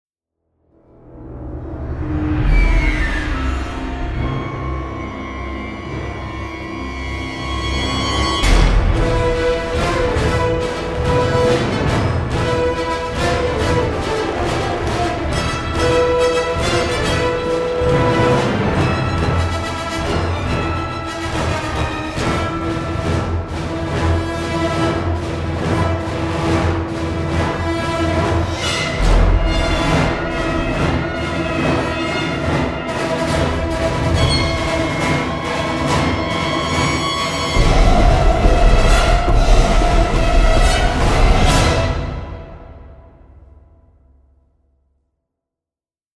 with a tense and modern sheen.